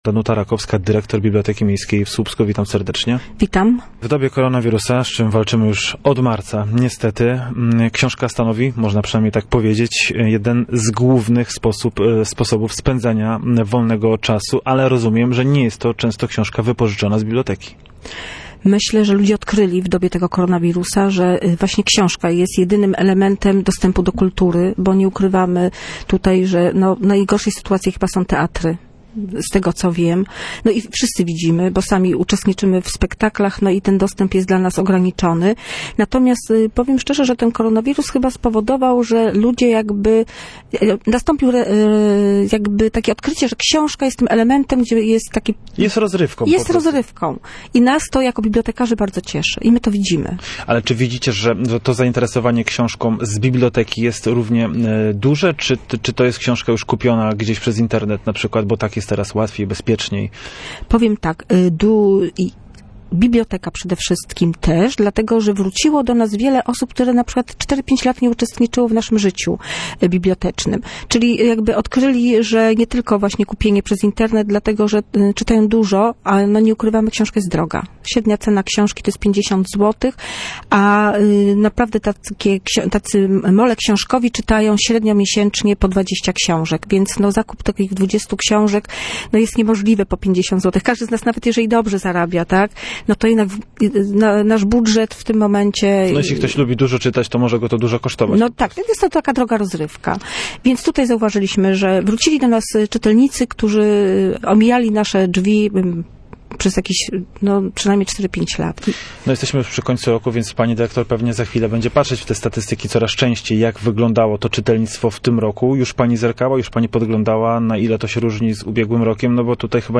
Zapraszamy do wysłuchania całej rozmowy.